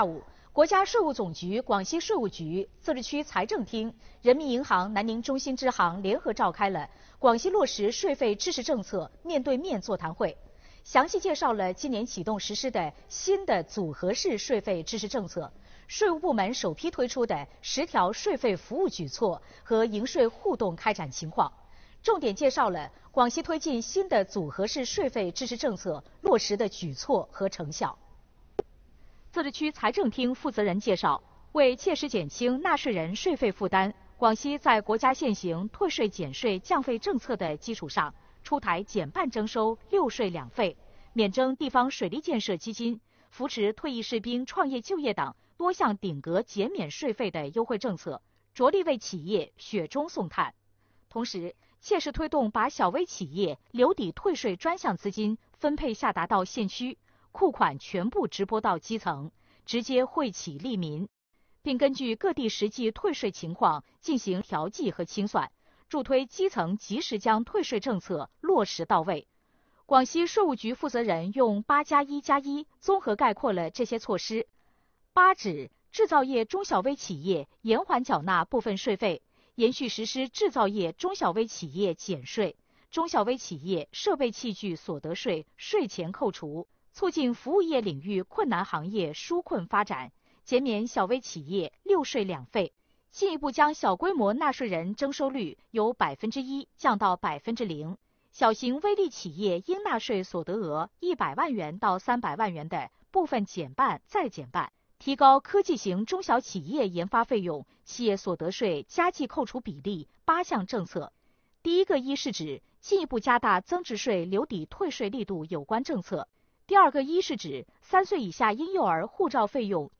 电视报道 | 广西召开落实税费支持政策“面对面”座谈会